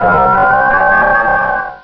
Cri_0350_DP.ogg